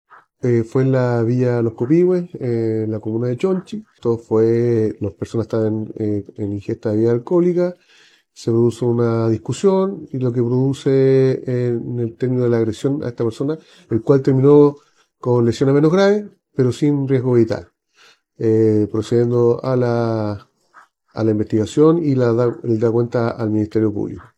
En tanto, a lo anterior se sumó durante el fin de semana un incidente en la comuna de Chonchi que terminó con una víctima herida por arma blanca, en el contexto de la ingesta de alcohol en la vía pública, según detalló el oficial uniformado.